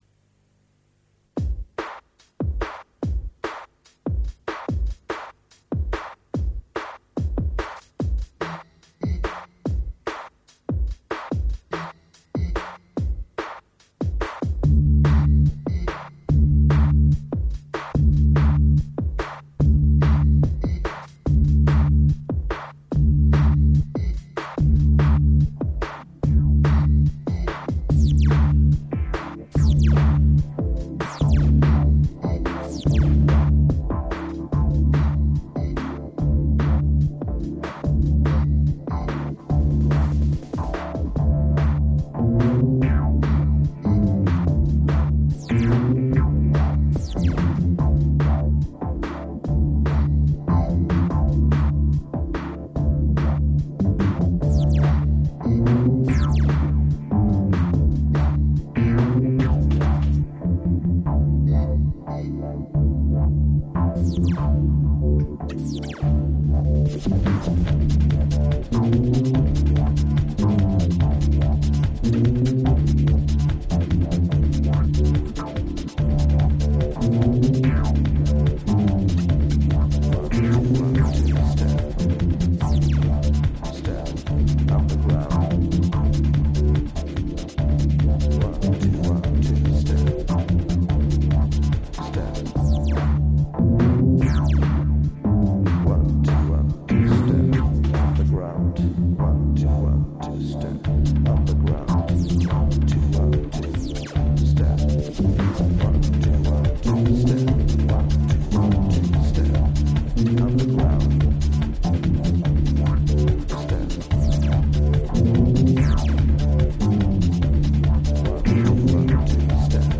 Since I have limited space on the server, the presented songs are only lower quality real audio samples.
2 STEP UNDERGROUND Trance Two-step